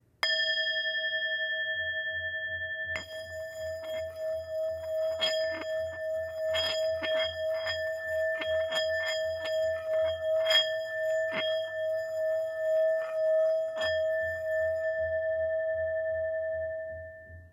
Tibetská mísa Jinpa malá
tibetska_misa_m29.mp3